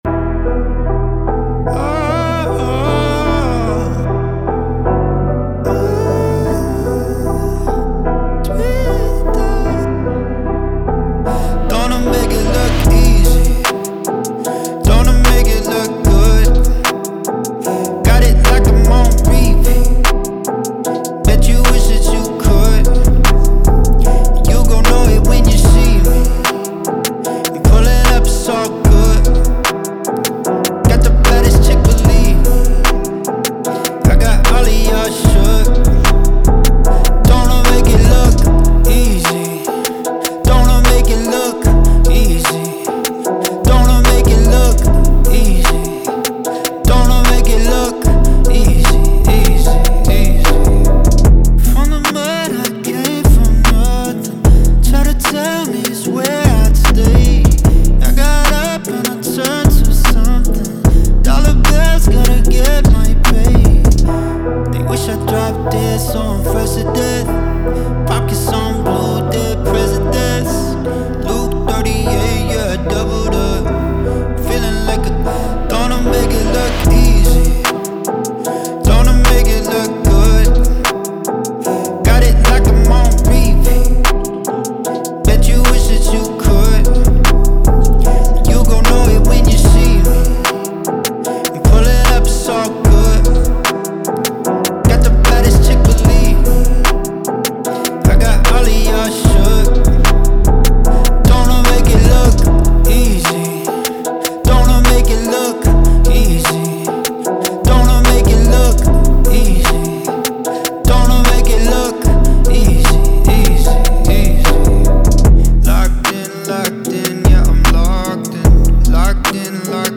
Trap, Hip Hop, Alternative R&B
E min